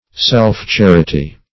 Self-charity \Self`-char"i*ty\, n.